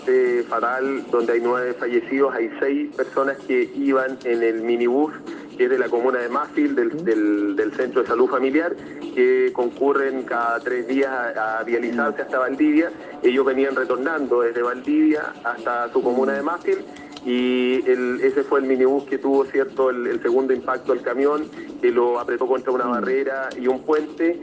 La violencia del impacto trajo consigo un trágico saldo, donde nueve personas -cinco hombres y cuatro mujeres- resultaron fallecidas y otras 11 presentaron lesiones de diversa consideración, debiendo ser trasladados a los centros asistenciales más cercanos, entre ellos el Hospital Regional de Valdivia. Así lo confirmó desde el lugar, el Intendente de la Región de Los Ríos, César Asenjo.